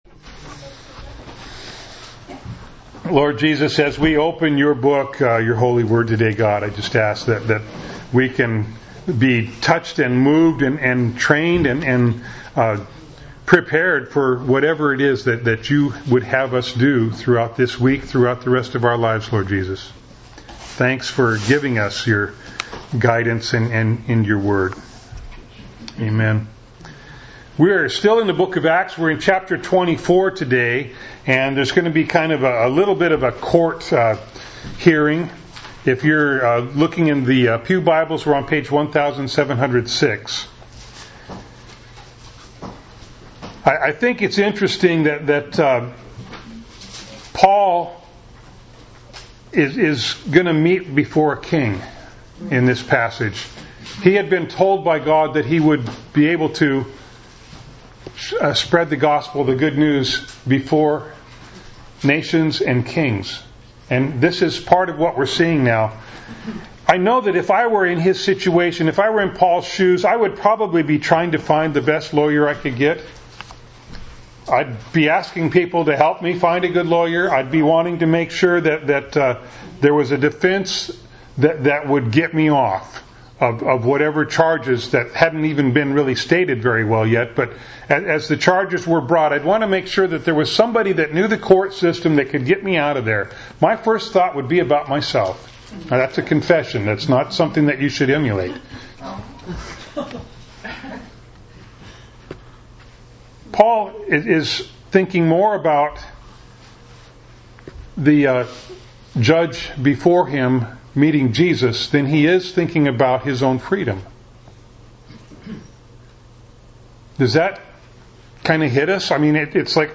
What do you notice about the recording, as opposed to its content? Acts 24:1-27 Service Type: Sunday Morning Bible Text